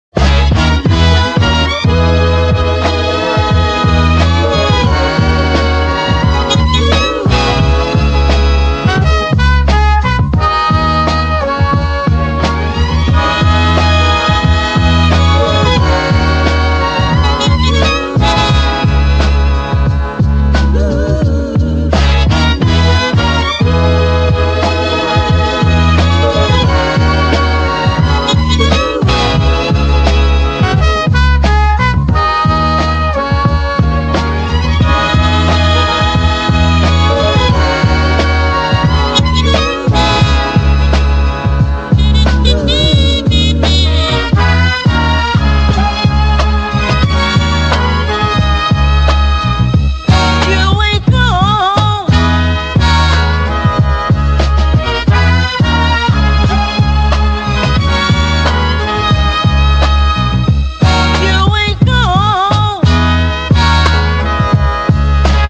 royalty-free sample pack